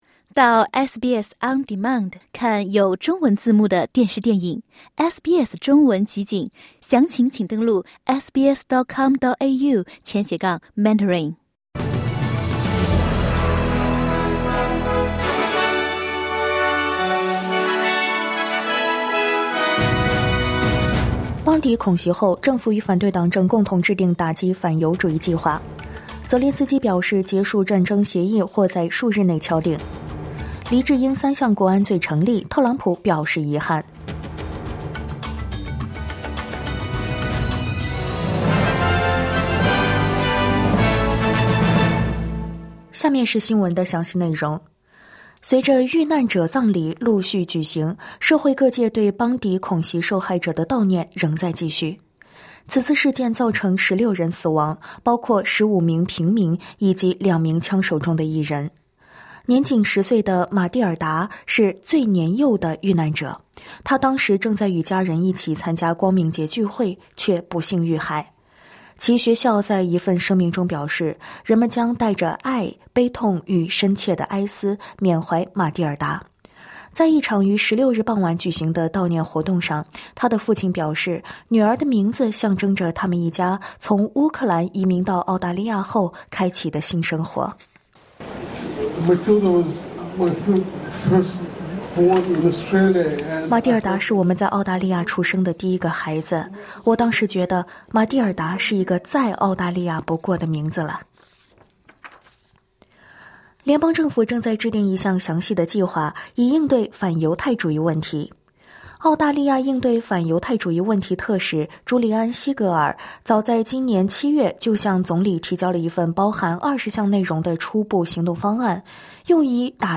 SBS早新闻（2025年12月17日）